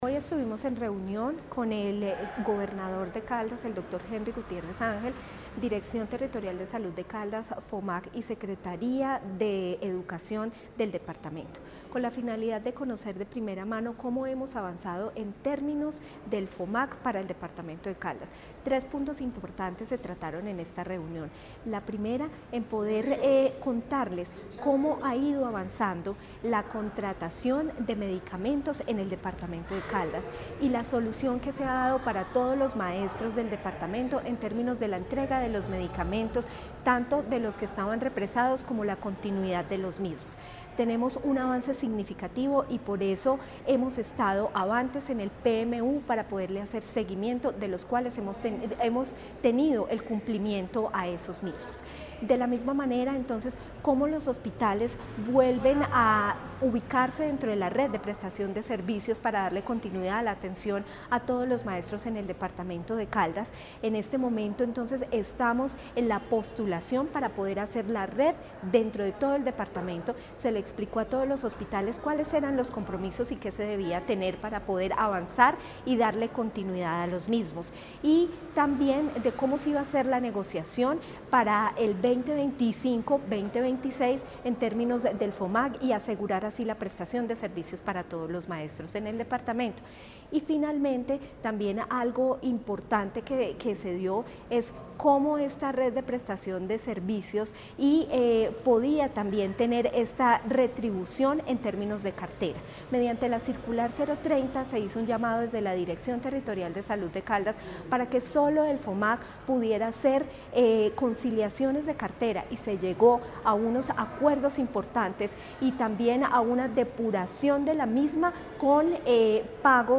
Natalia Castaño Díaz, directora de la DTSC.
AUDIO-NATALIA-CASTANO-DIAZ-DIRECTORA-DTSC-TEMA-FOMAG.mp3